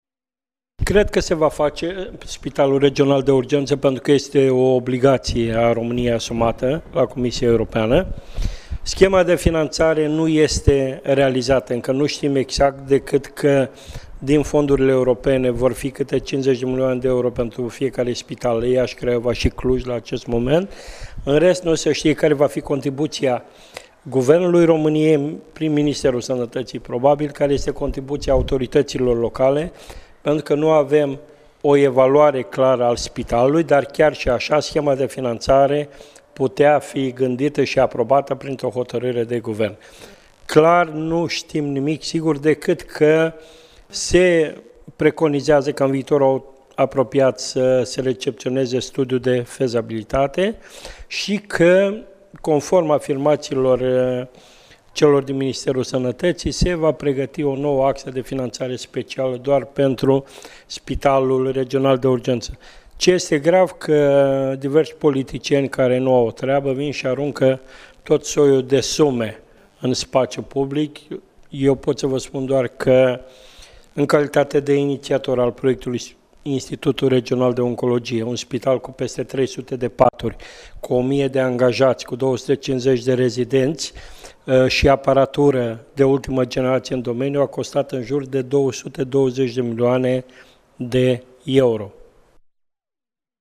Principalele declaratii ale presedintelui PMP Iasi, Petru Movila